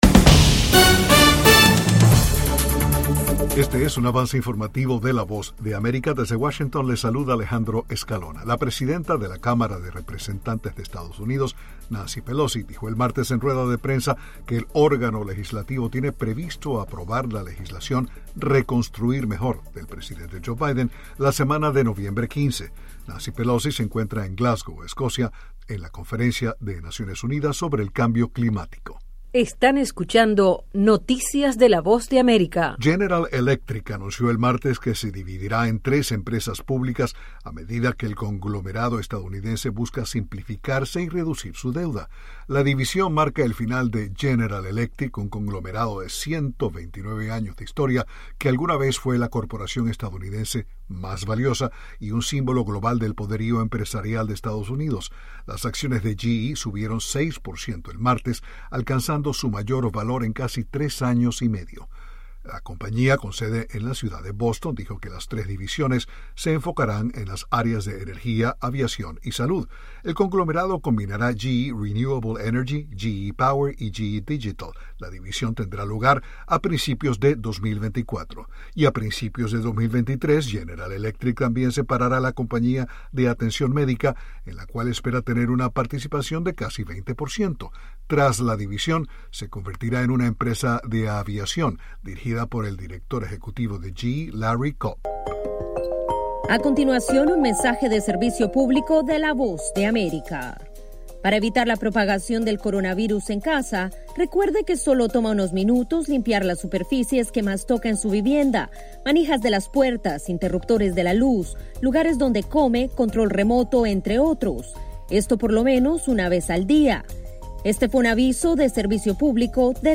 Avance informativo 4:00pm